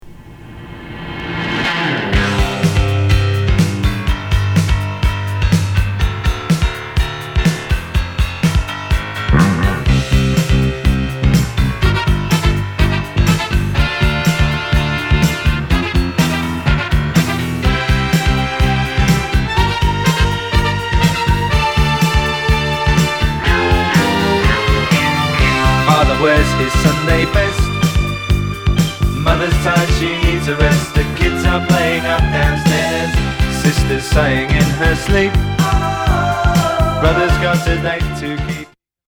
ことは完璧なハッピー・ダンシン・ナムバー！！